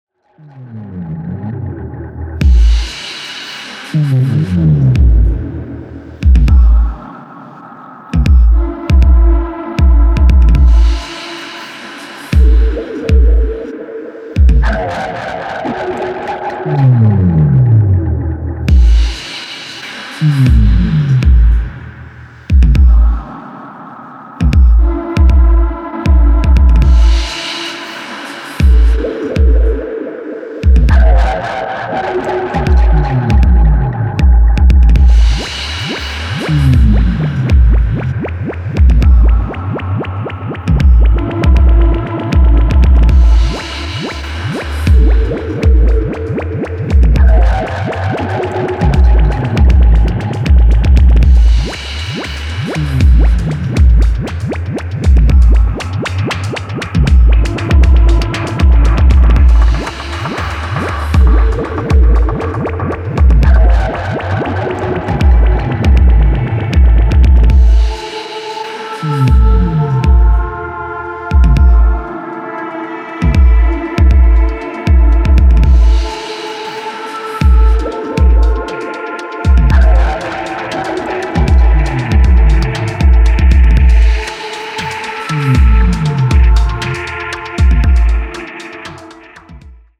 Electronix Techno Ambient